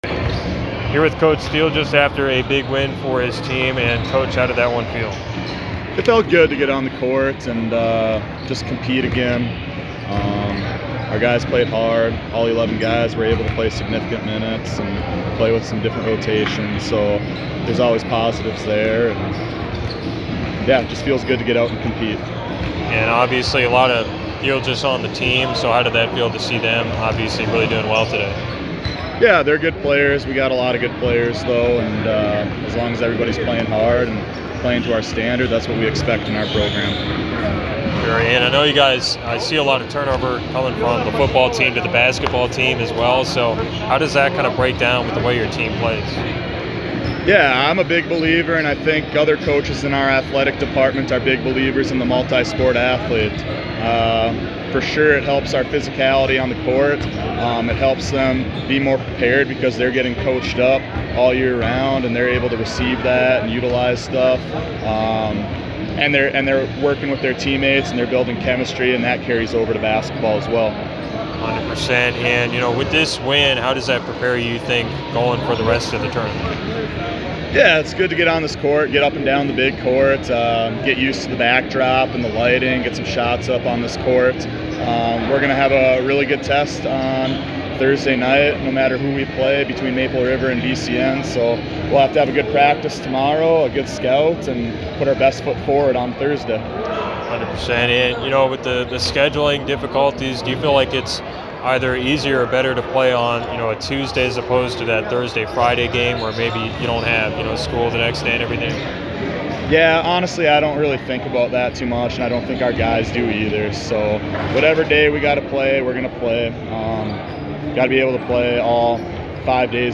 Interview audio